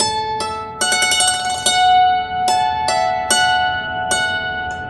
Dulcimer09_98_G.wav